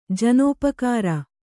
♪ janōpakāra